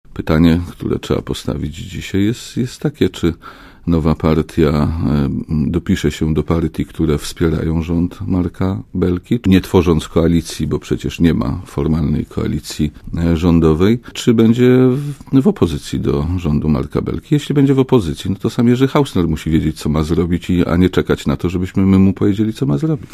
Obecność Jerzego Hausnera w nowej partii to oczywisty kłopot dla rządu Marka Belki - mówi minister obrony i wiceprzewodniczący SLD Jerzy Szmajdziński, poniedziałkowy gość Radia ZET.
Mówi Jerzy Szmajdziński